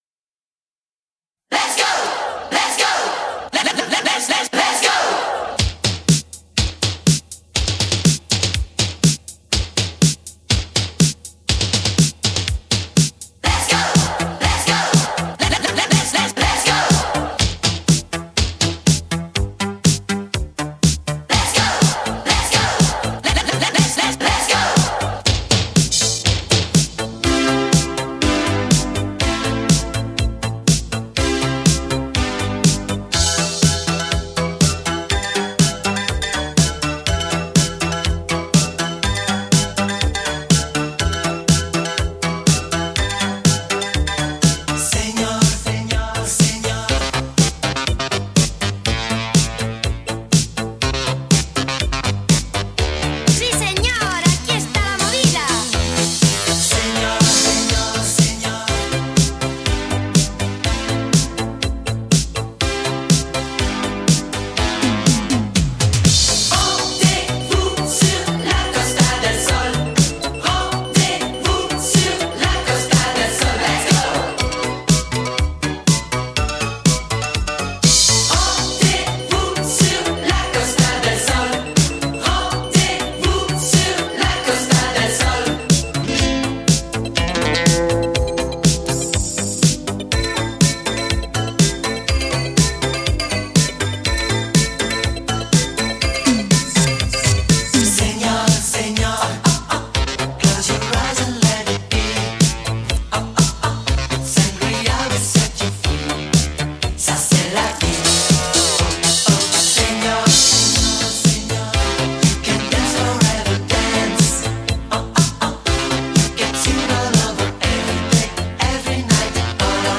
80年代最经典舞曲